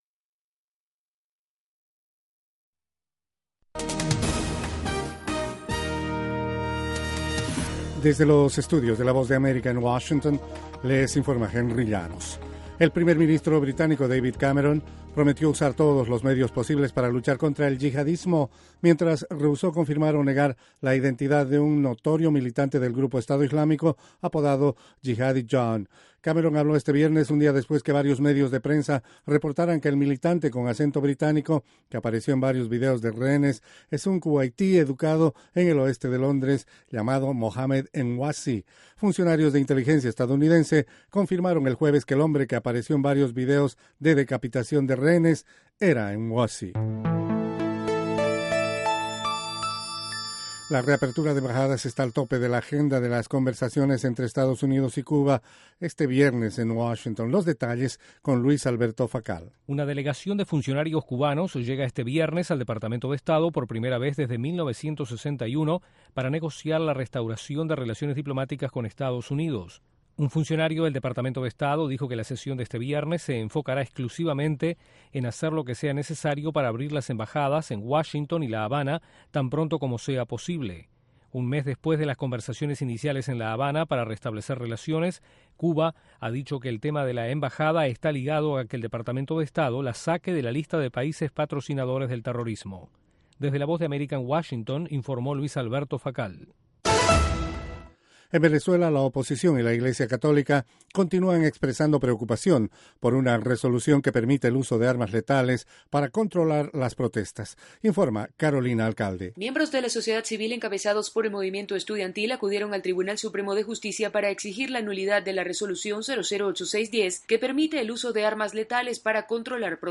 Informativo VOASAT